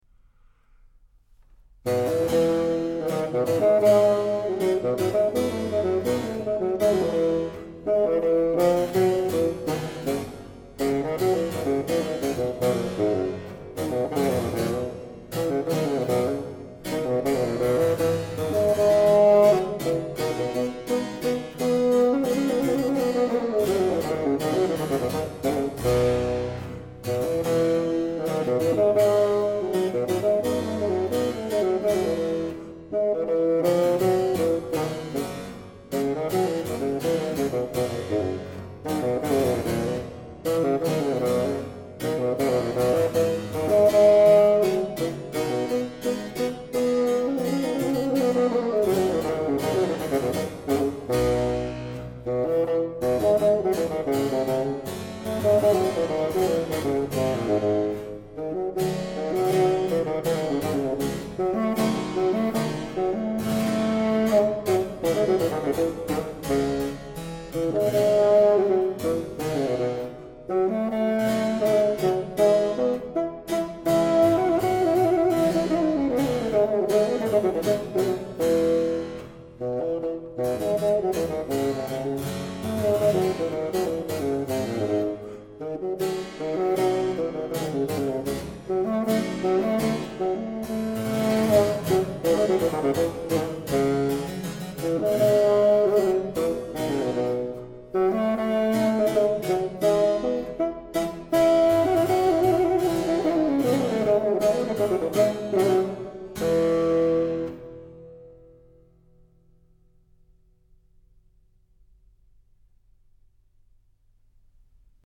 Os discos são muito bem gravados e bonitos.